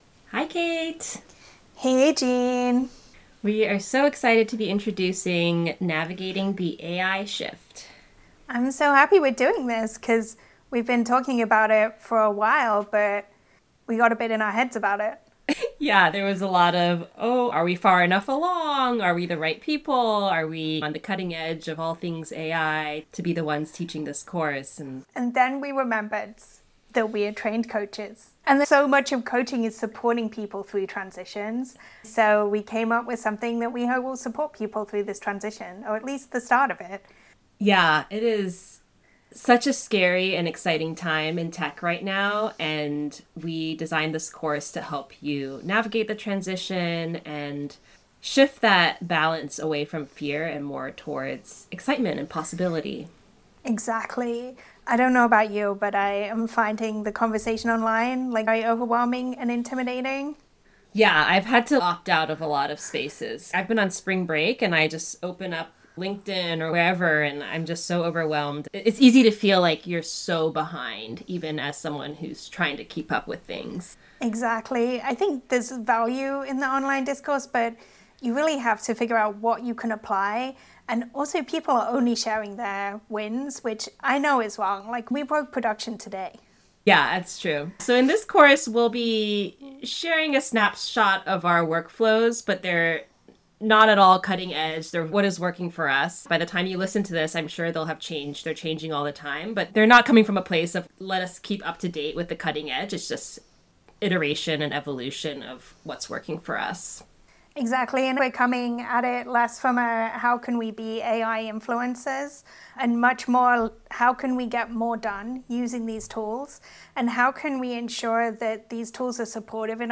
Audio Conversation